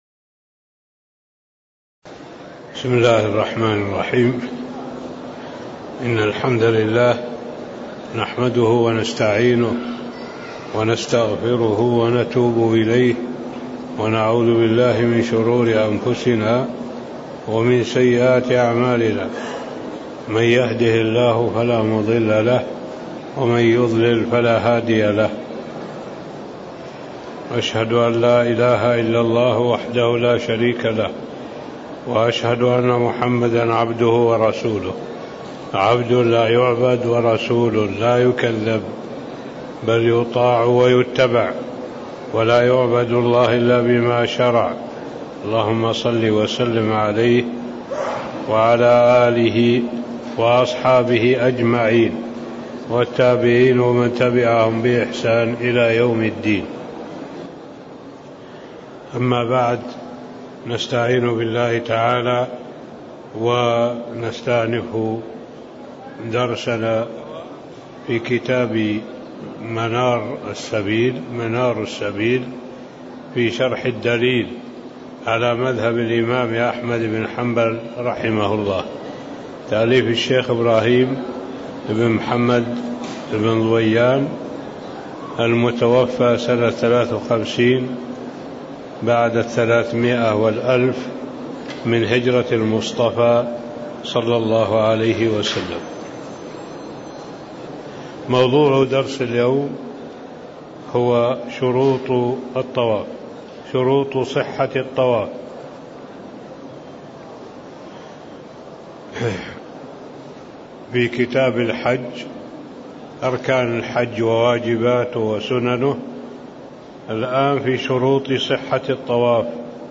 تاريخ النشر ١ ذو القعدة ١٤٣٦ هـ المكان: المسجد النبوي الشيخ